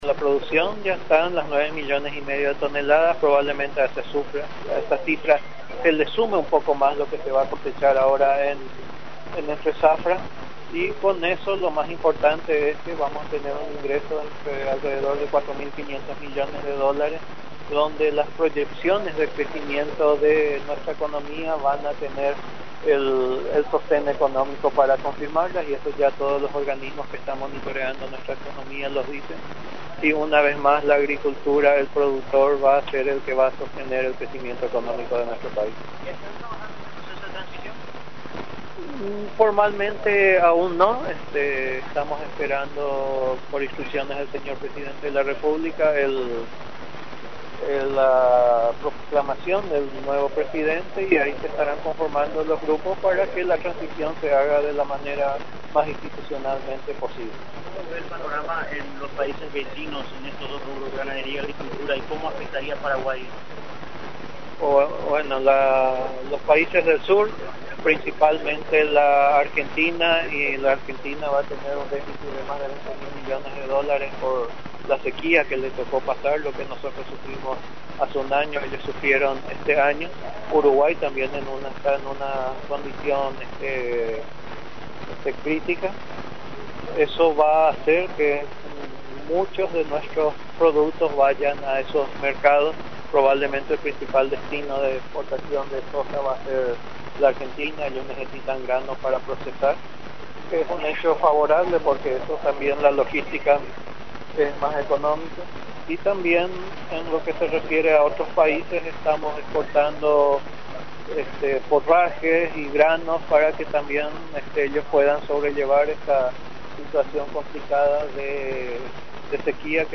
El Ministro Agricultura y Ganadería, Moises Santiago Bertoni en conferencia de prensa en el acto de apertura de la Expo Neuland en Boquerón dijo, que la producción alcanza más de 9 millones de toneladas ésta zafra lo que representa un ingreso de más de 4 mil quinientos millones de dólares, donde las proyecciones de incremento de nuestra economía, una vez más, la agricultura y el productor es el que va a sostener dicho crecimiento económico de nuestro país.